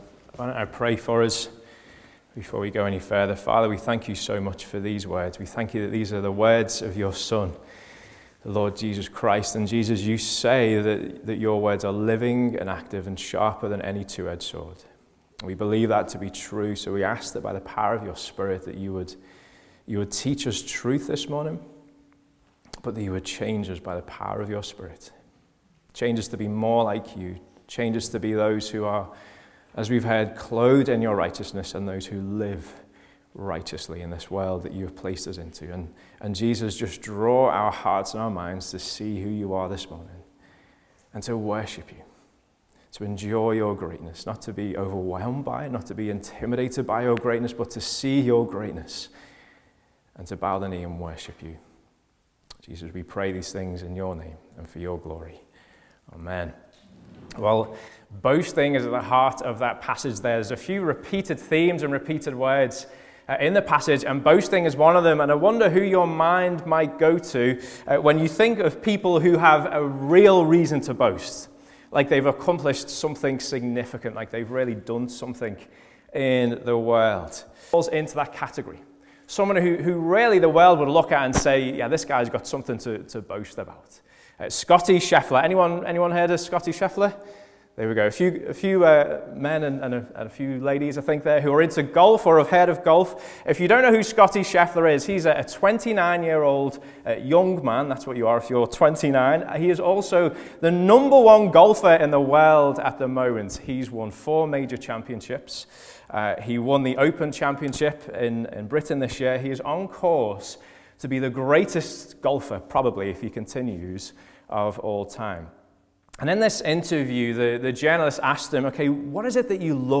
Preaching and teaching from Aigburth Community Church